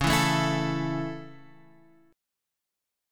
A5/C# chord